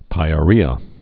(pīə-rēə)